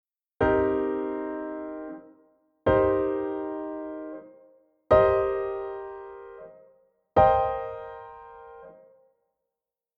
Here is an example of the inversions using a C Maj7…